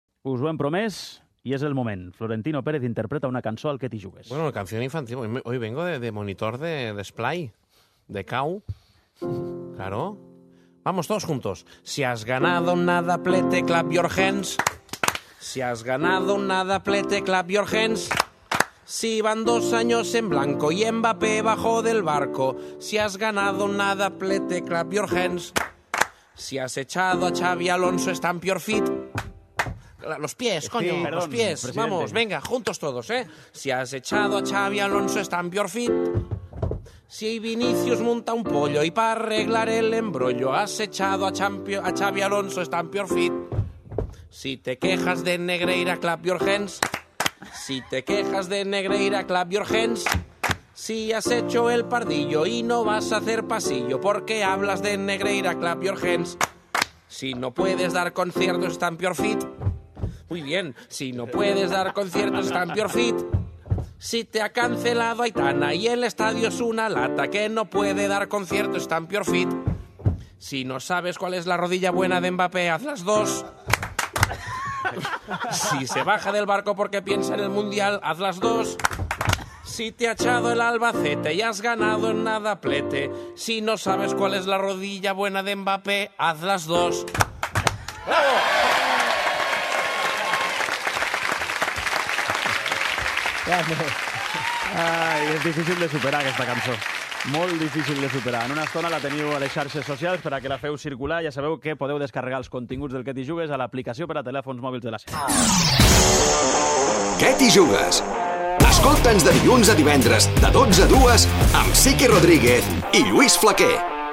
Al 'Què t'hi jugues!', el nostre president del Madrid versiona un clàssic infantil per definir la temporada en blanc dels seus jugadors